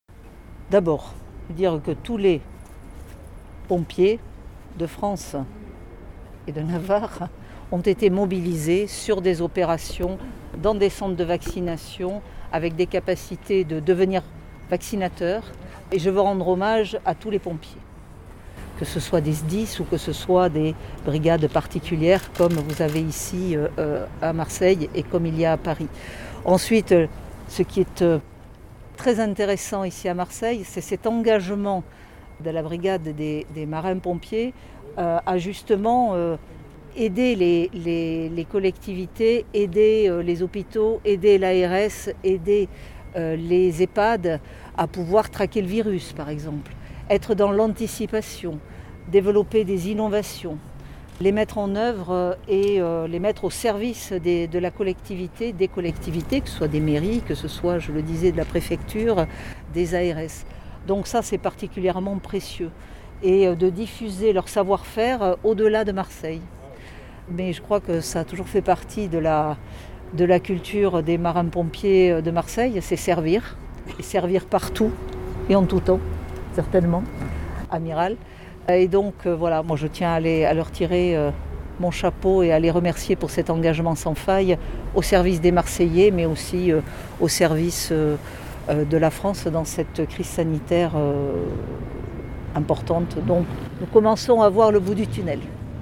A l’issue des ces visites Geneviève Darrieussecq a répondu aux questions de Destimed.